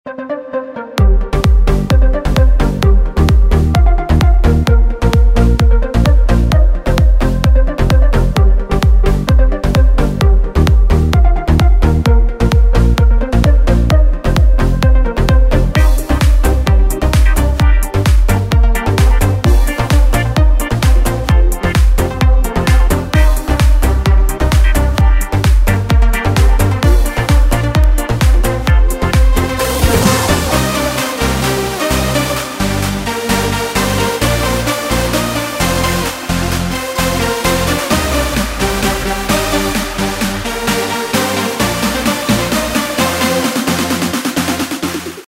ז'אנרDance
BPM130